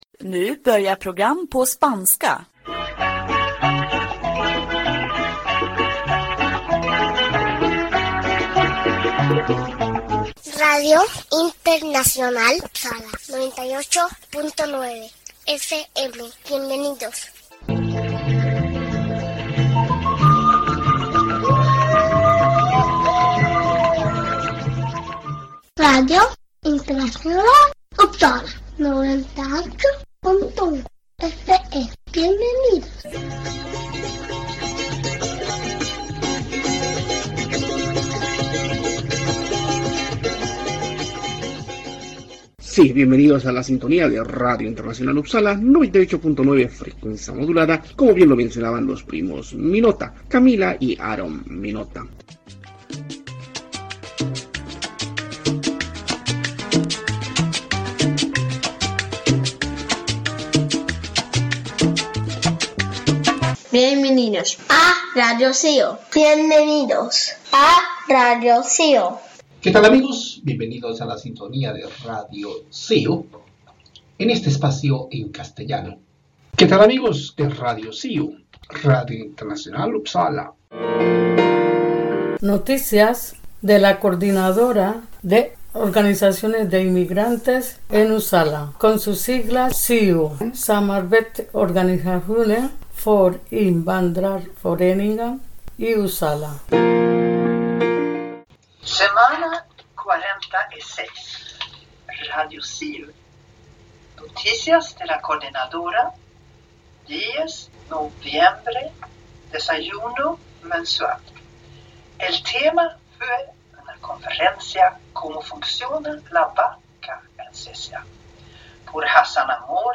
Sí, radio de cercanías en Uppsala se emite domingo a domingo a horas 18:30.